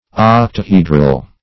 octahedral - definition of octahedral - synonyms, pronunciation, spelling from Free Dictionary
octahedral \oc`ta*he"dral\ ([o^]k`t[.a]*h[=e]"dral), a. [See